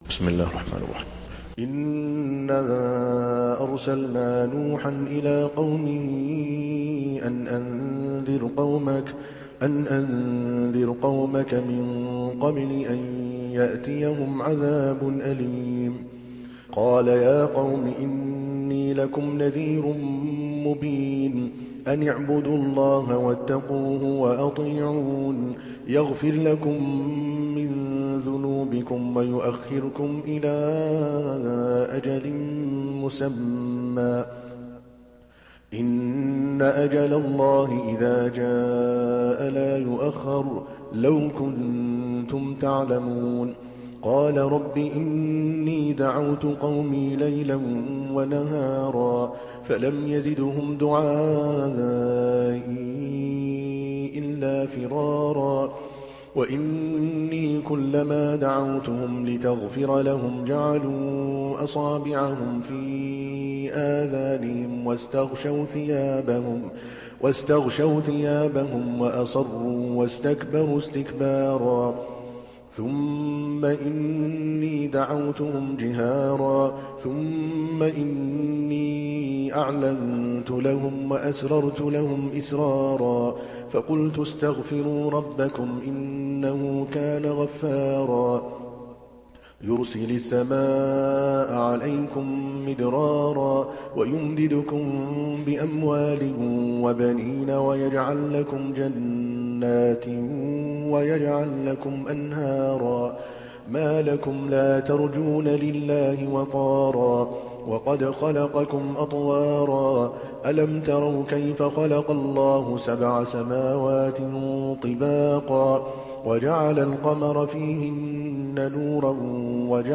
دانلود سوره نوح mp3 عادل الكلباني روایت حفص از عاصم, قرآن را دانلود کنید و گوش کن mp3 ، لینک مستقیم کامل